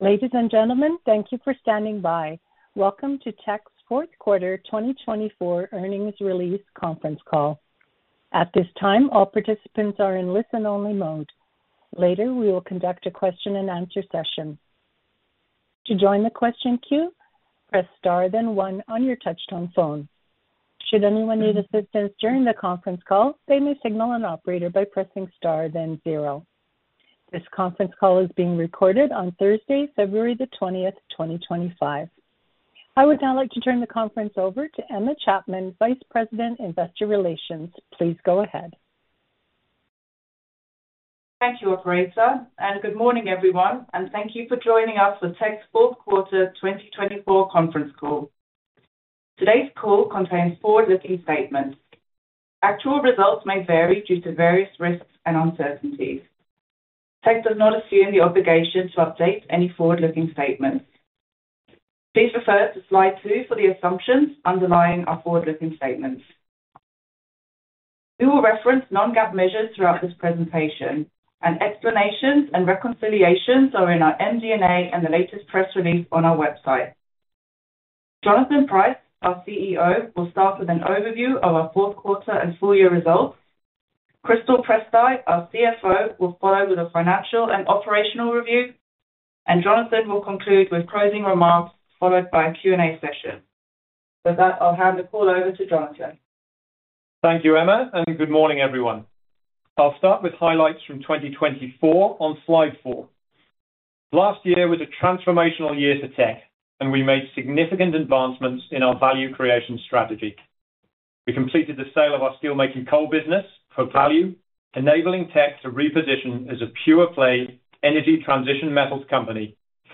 Q4-2024-Conference-Call-Recording.mp3